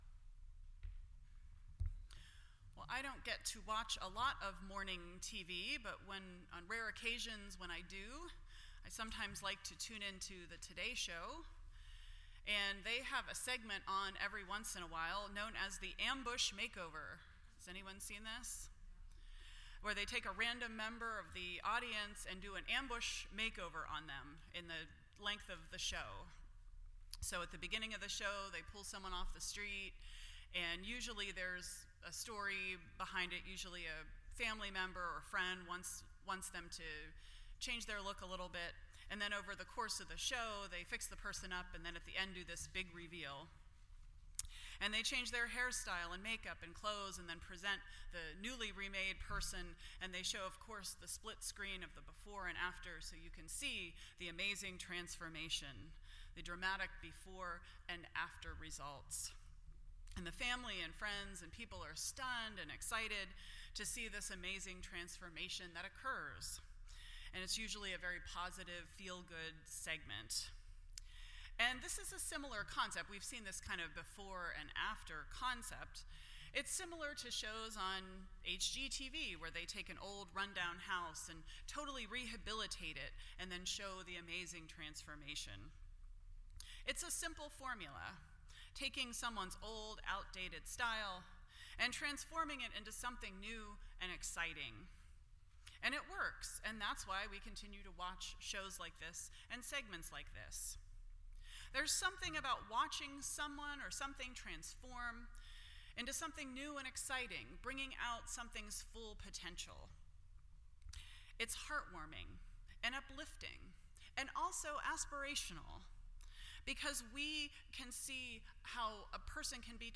Series: Ephesians Service Type: Sunday Morning %todo_render% Share This Story